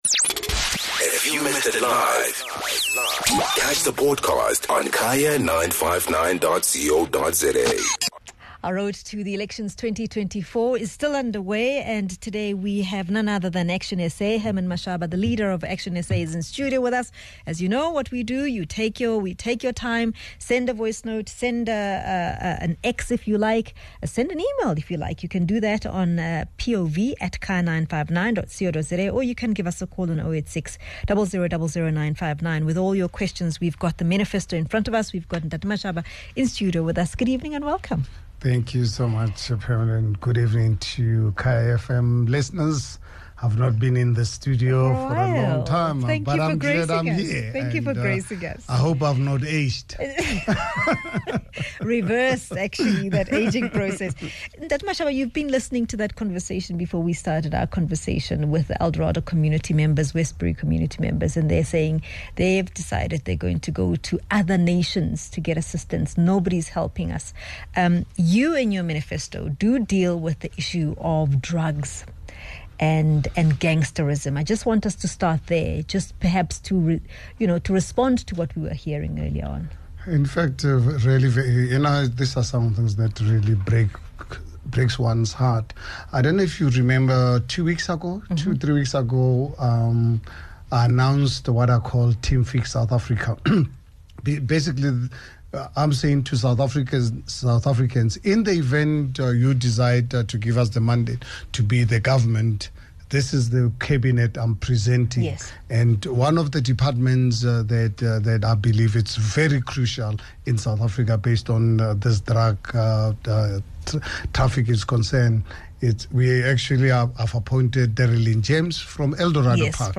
joined in studio by ActionSA Leader Herman Mashaba to unpack his party’s election manifesto, focusing on job creation, hijacked buildings securing borders, and the economy amongst other issues.